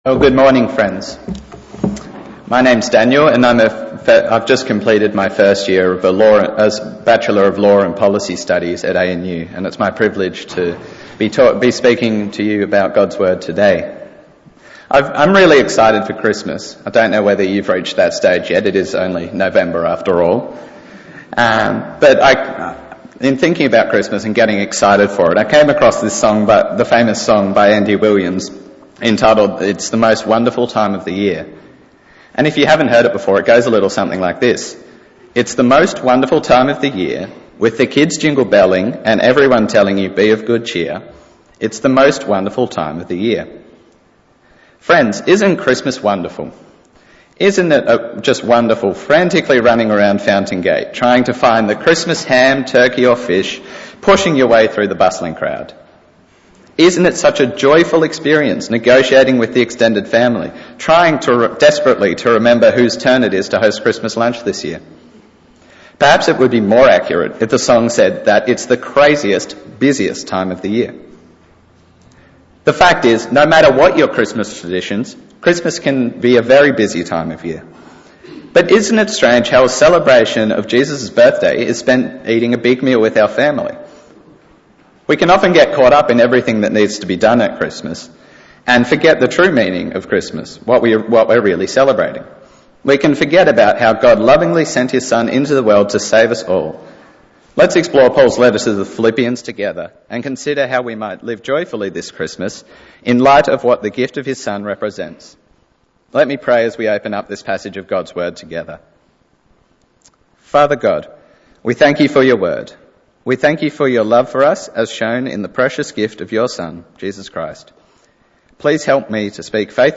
Bible Text: Philippians 4:6-12 | Preacher